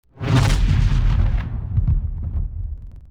StarProbeLaunch.wav